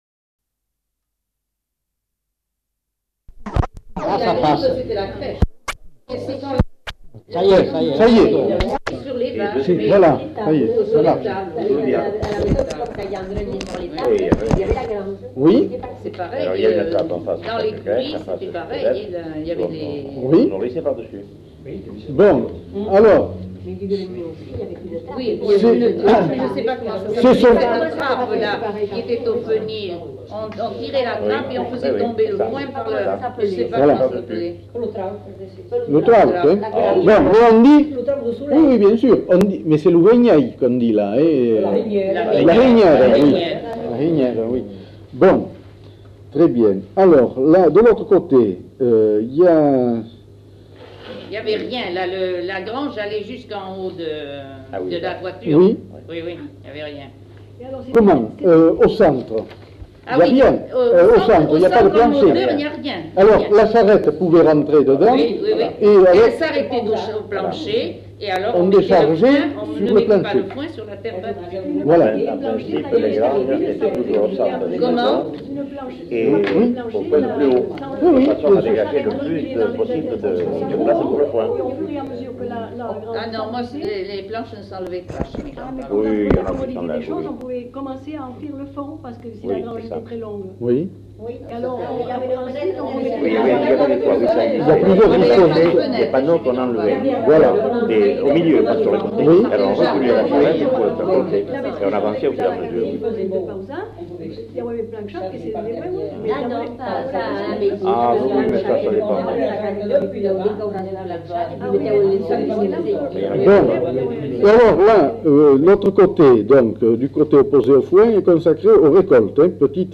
Discussion avec éléments de vocabulaire occitan
Nature du document : enquête
Type de son : mono
Qualité technique : moyen
Lieu : Bazas